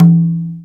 MADAL 4A.WAV